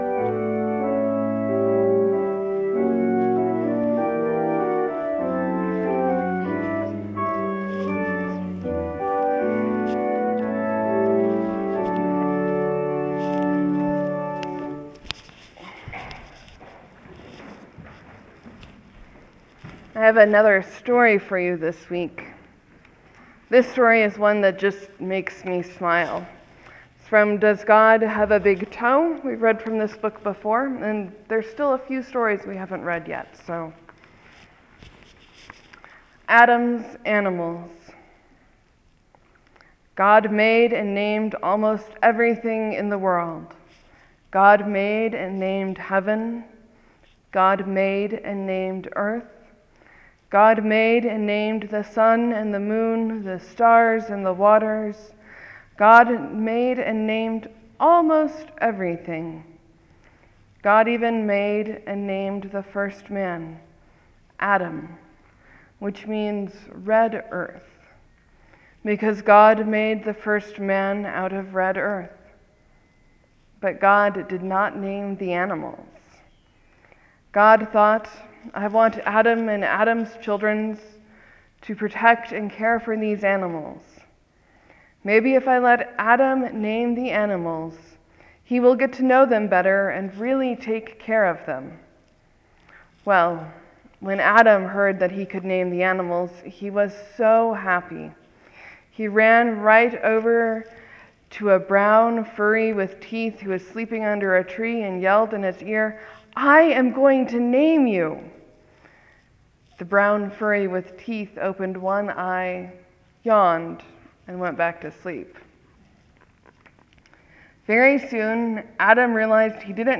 Morsels & Stories: I read “Adam’s Animals” from Does God have a Big Toe?
Sermon: Jesus feels compassion when he sees a widow burying her only son. What would Jesus see in our city that would spark gut-twisting compassion?